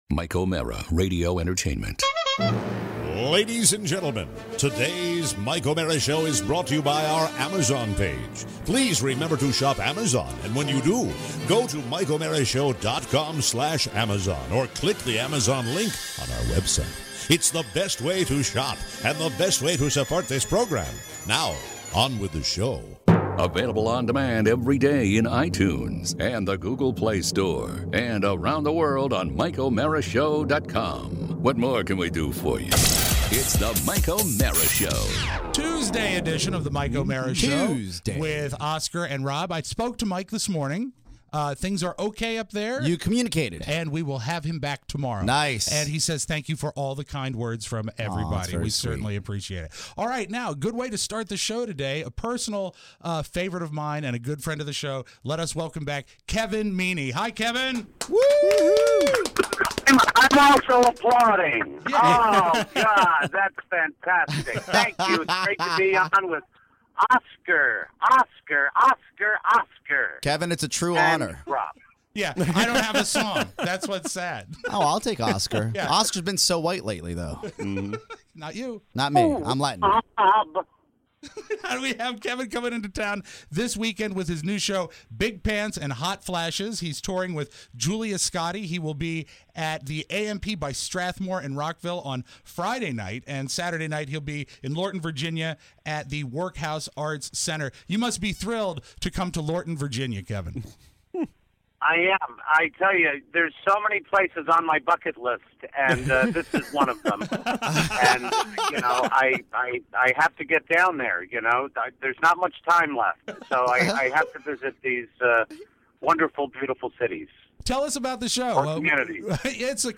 A wonderful phoner with comedian Kevin Meaney!